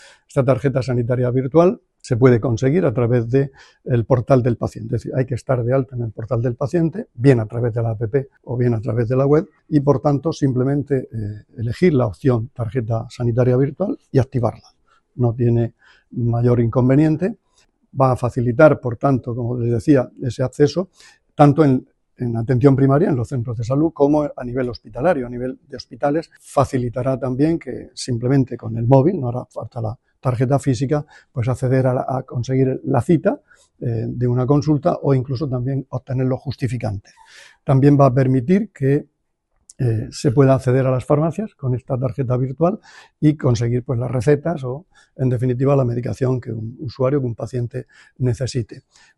Declaraciones del consejero de Salud, Juan José Pedreño, durante la presentación de la tarjeta sanitaria virtual. [mp3]